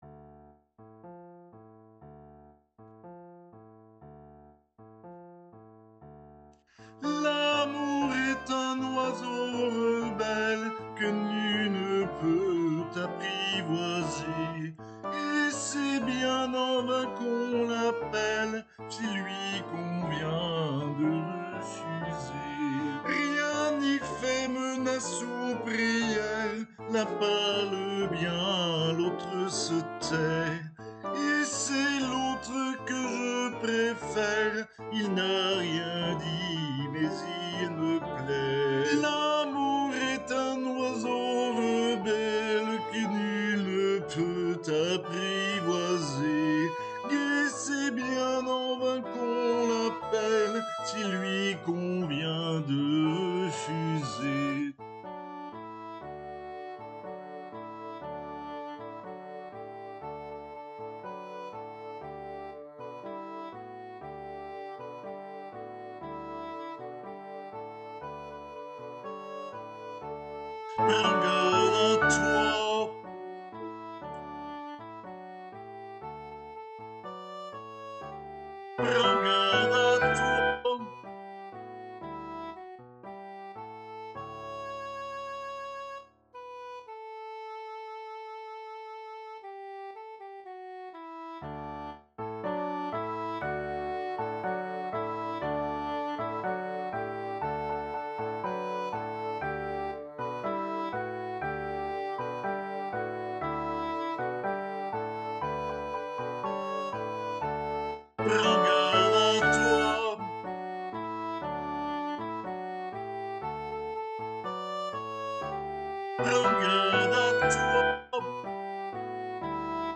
voix chantée
Habanera-Alti.mp3